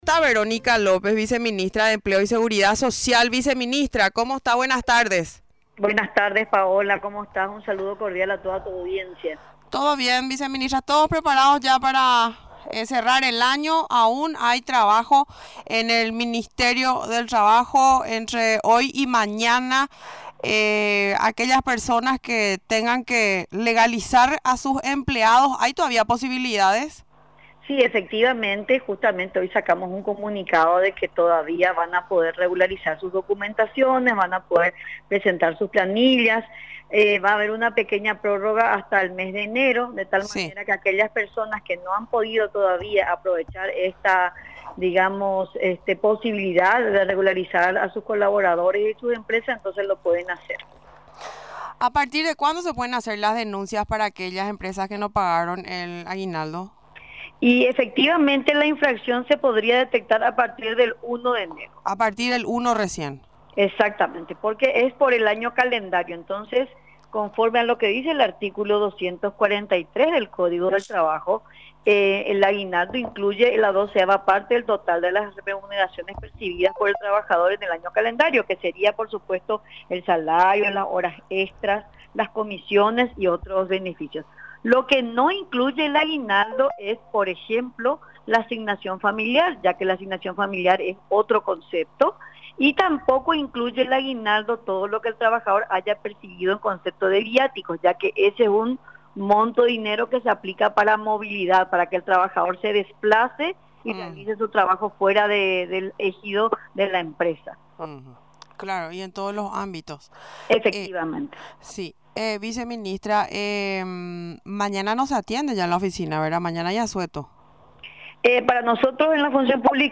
Explicó, durante la entrevista en Radio Nacional del Paraguay, que las denuncias se pueden realizar de manera presencial en la sede central, en Herrera y Paraguarí, de Asunción, o en las Oficinas Regionales de Trabajo, de lunes a viernes, de 07:00 a 15:00 horas.